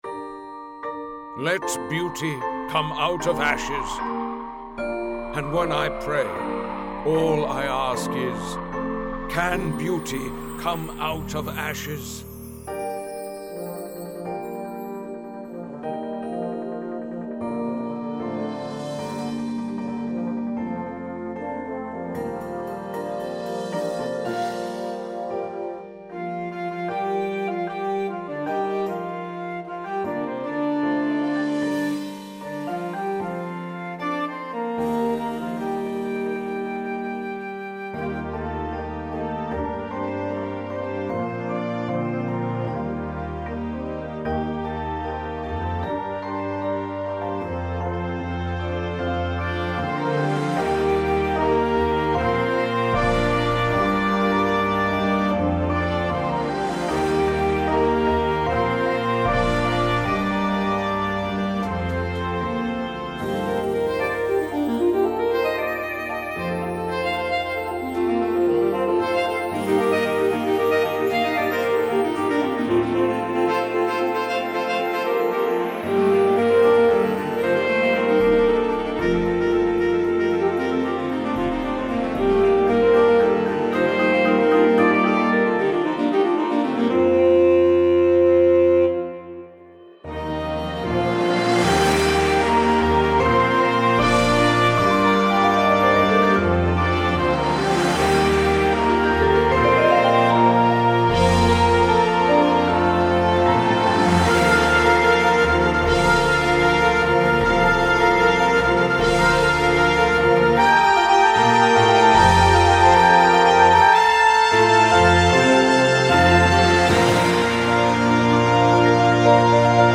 • Flute
• Clarinet 1, 2
• Alto Sax 1, 2
• Trumpet 1
• Horn in F
• Trombone 1, 2
• Tuba
• Snare Drum
• Synthesizer – Two parts
• Marimba – Two parts
• Vibraphone – Two parts